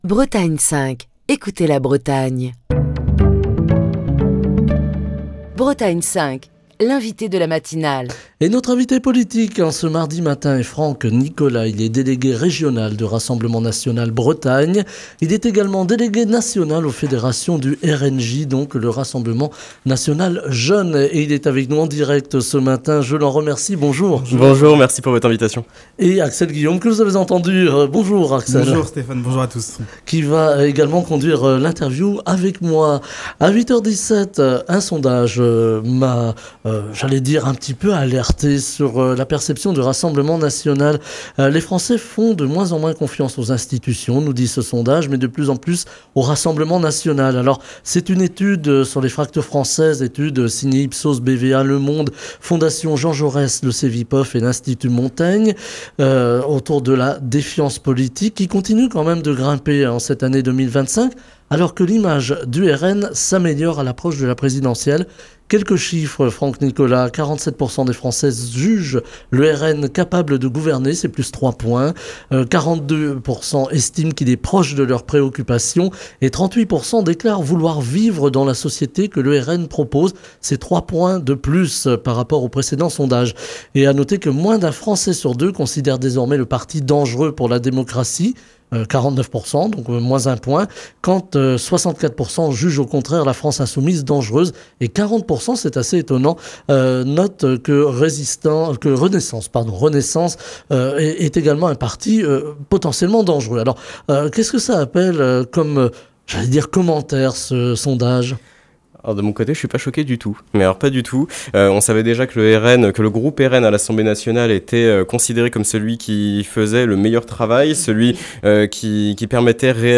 était l'invité politique de la matinale de Bretagne 5 ce mardi.